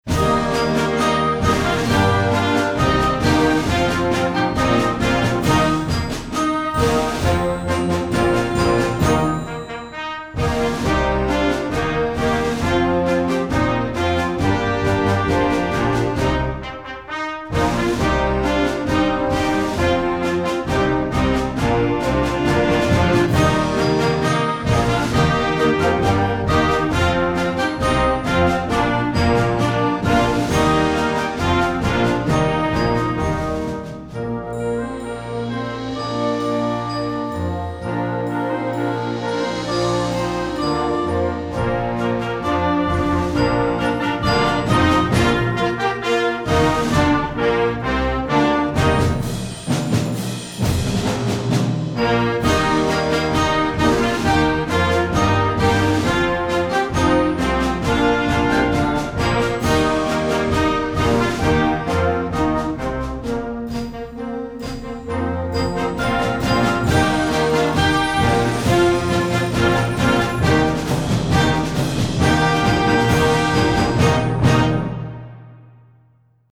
Instrumental Concert Band Marches
Concert Band